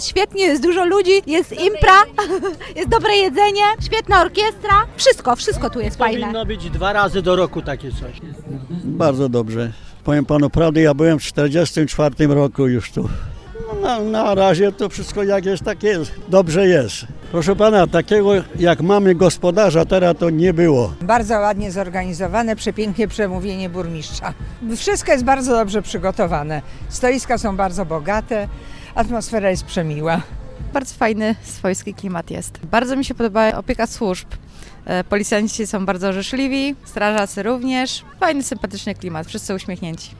Uczestnicy dożynek podzielili się z nami swoimi wrażeniami ze wspólnej zabawy.